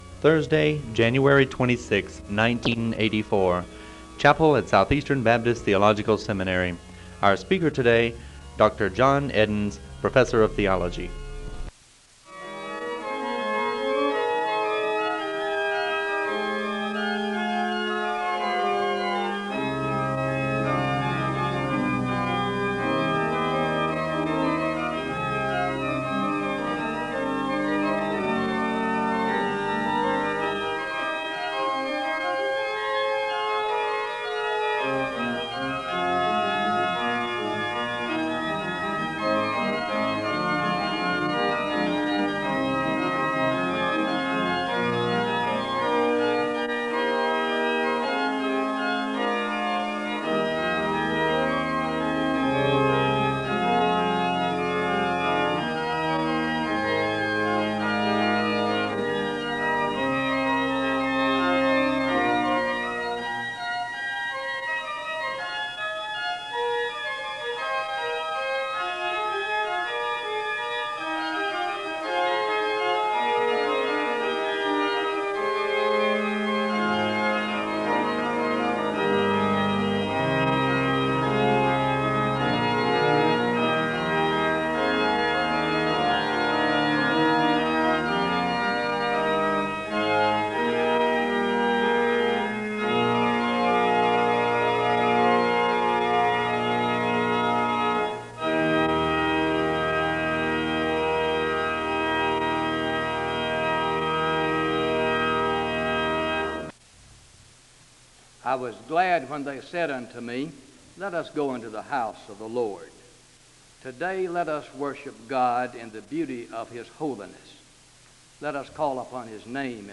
The service begins with organ music (00:00-01:46).
The choir sings a song of worship (03:15-05:15...
SEBTS Chapel and Special Event Recordings SEBTS Chapel and Special Event Recordings